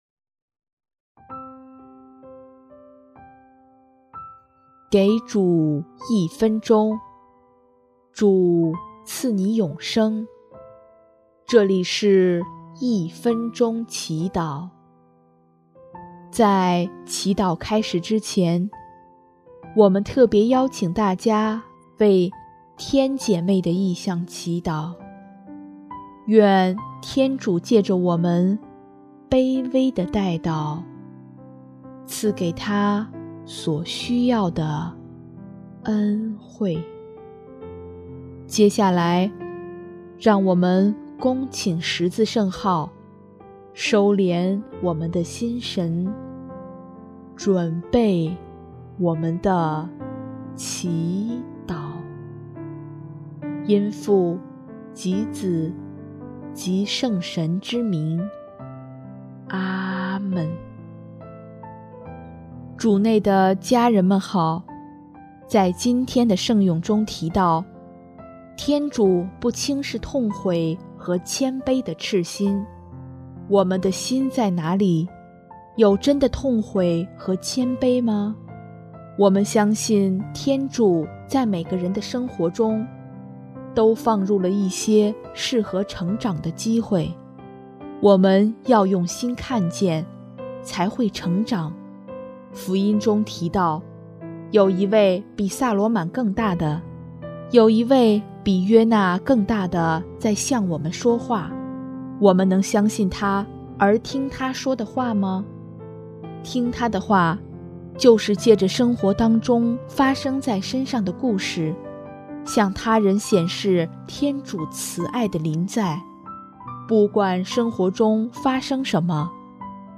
音乐：主日赞歌《如果你们爱我》